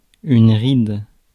Ääntäminen
UK : IPA : /ɹɪdʒ/ US : IPA : [ɹɪdʒ]